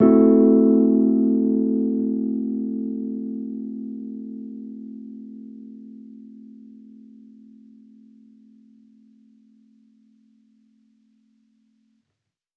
Index of /musicradar/jazz-keys-samples/Chord Hits/Electric Piano 1
JK_ElPiano1_Chord-Am6.wav